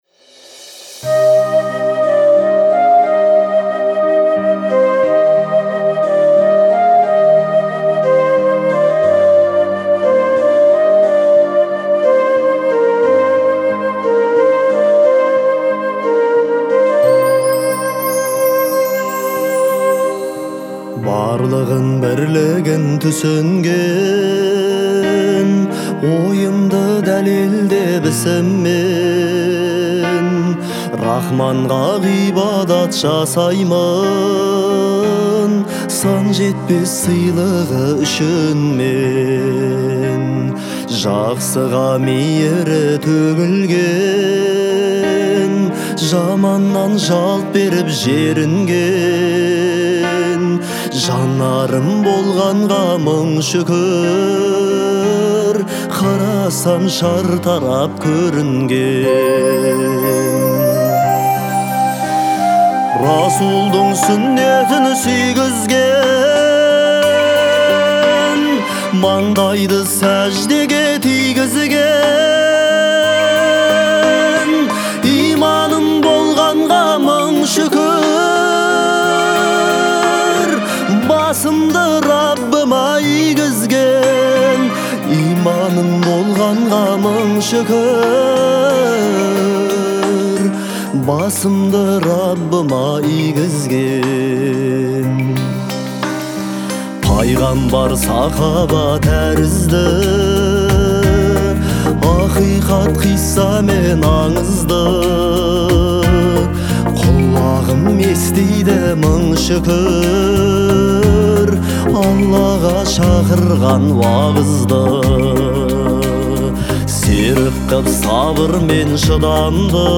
Категория: Казахские песни